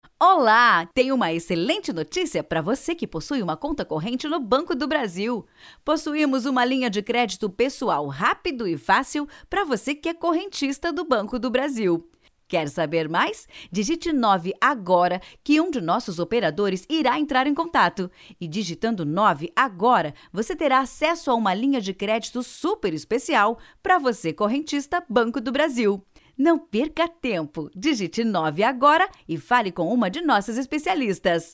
OFF BANCO BRASIL: